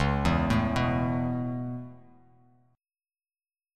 Dbm7#5 chord